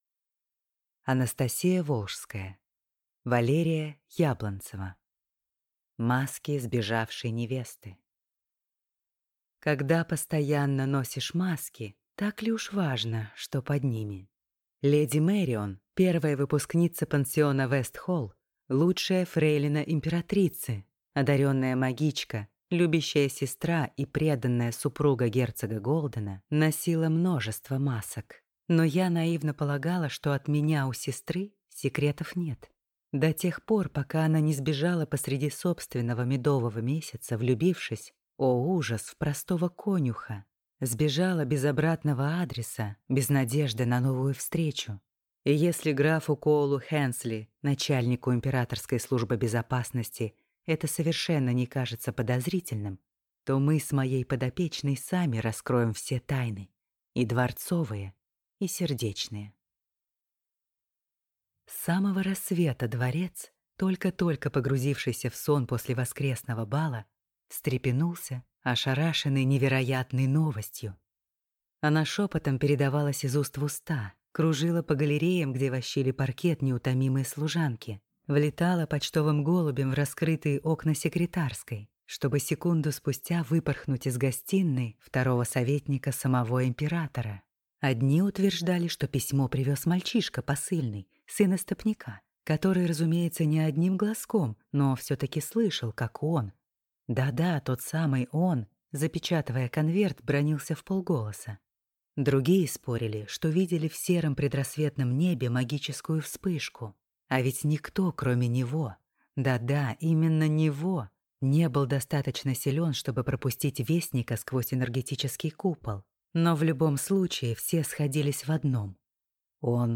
Аудиокнига Маски сбежавшей невесты | Библиотека аудиокниг